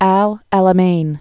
(ăl ălə-mān, älə-)